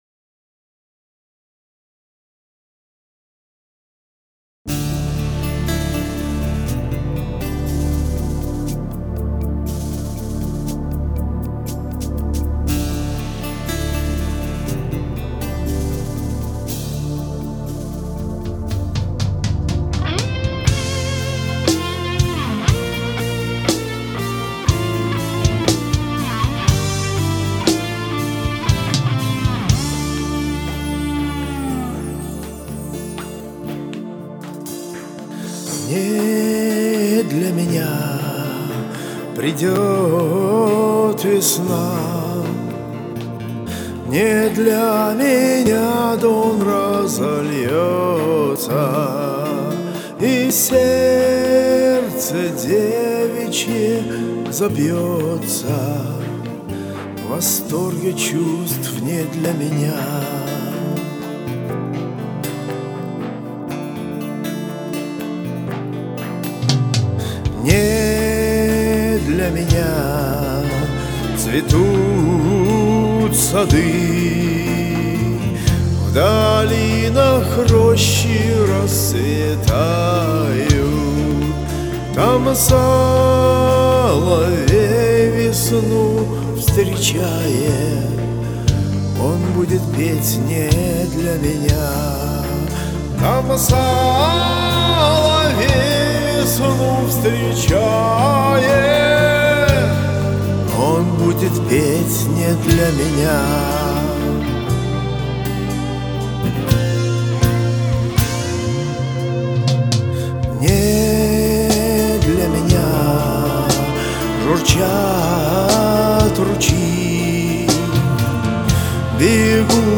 Народная казачья
Запись, демо, не отработана по записи и вокально.